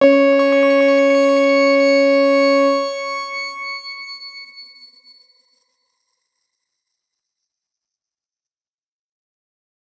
X_Grain-C#4-ff.wav